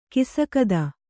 ♪ kisakada